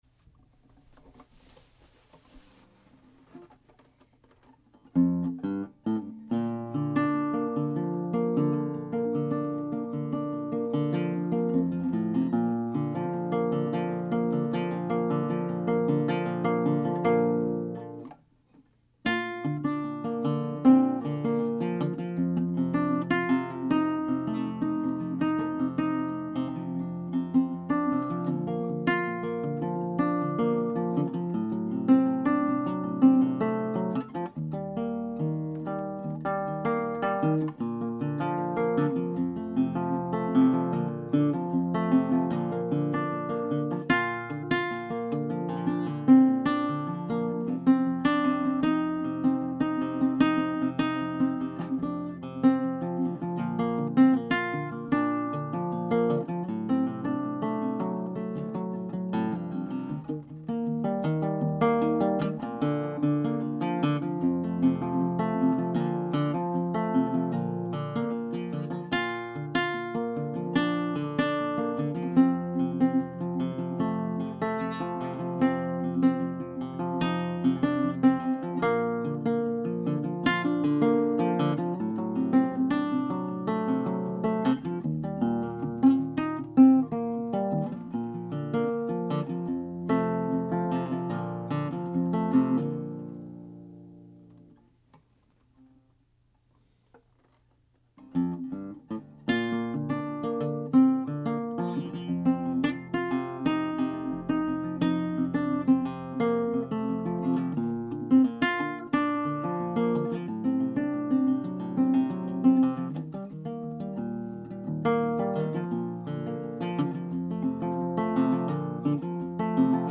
Stauffer Style Antique Guitar
I put some new strings on it (D’Addario Nylon Folk).
It is surprisingly loud for such a little guy.
The guitar has maple back and sides and a spruce top.
Freight Train. I didn’t use any effects, just a couple of mikes about 2 feet away recorded to my Xoom H4N digital recorder.